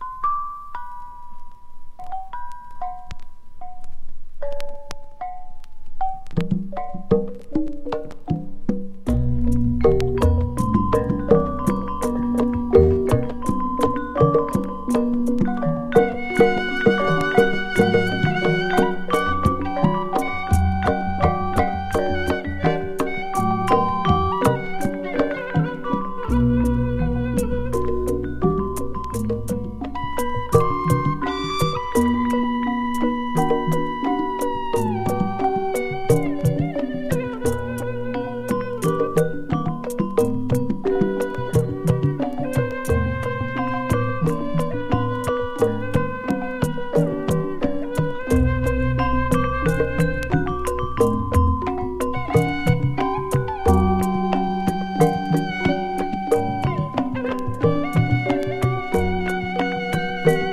コンテンポラリー感覚のガムラン・インスト。